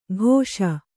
♪ ghōṣa